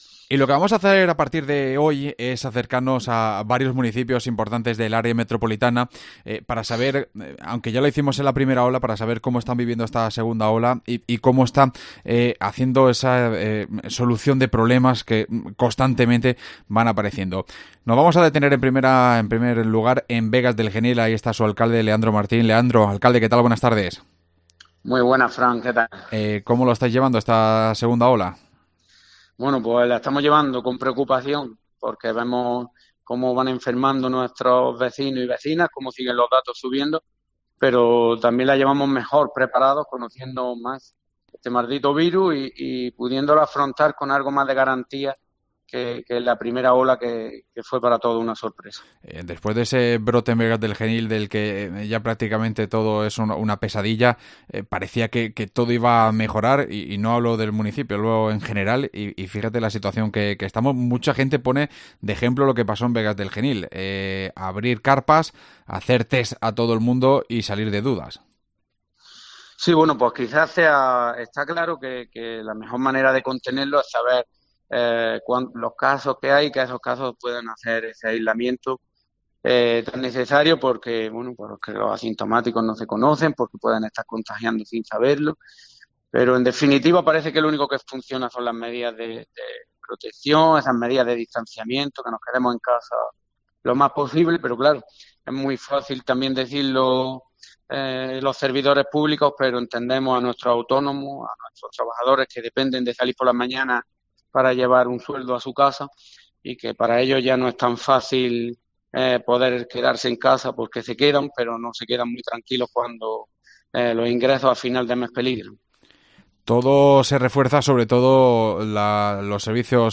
AUDIO: Hablamos con su alcalde, Leandro Martín.